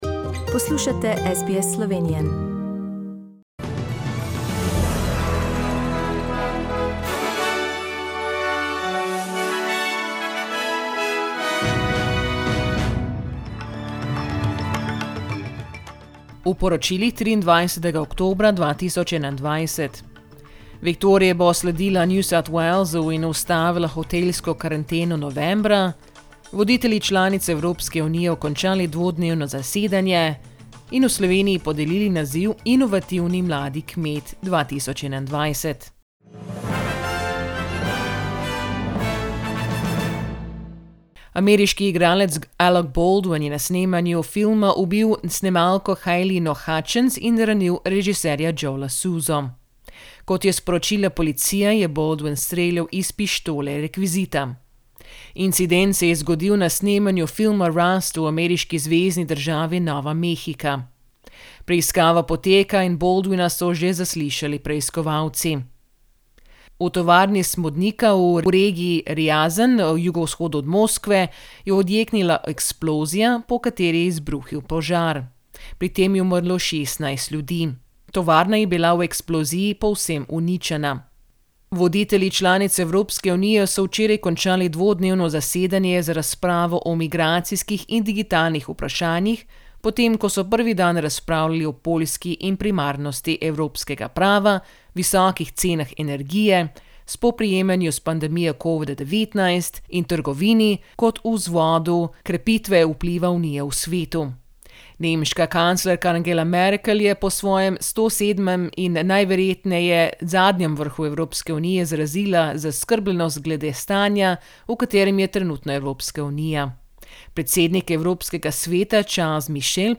Poročila Radia SBS v slovenščini 23.oktobra